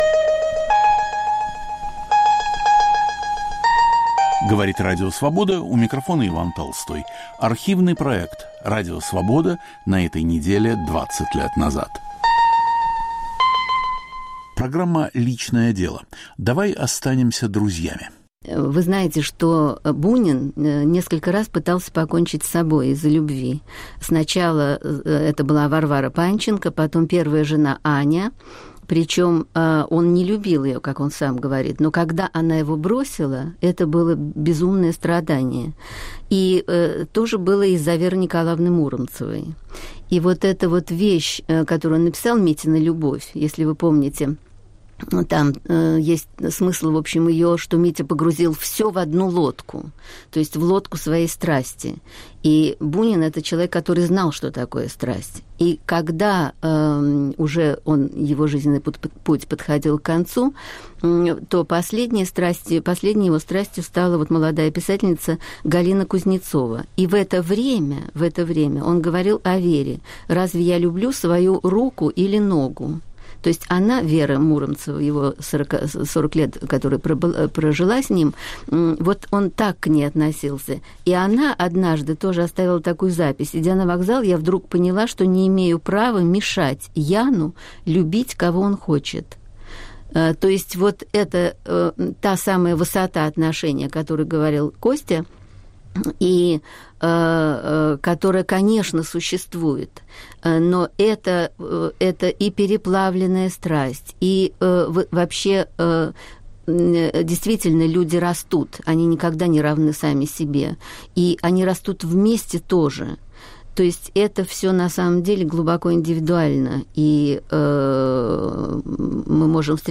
беседует в Московской студии Радио Свобода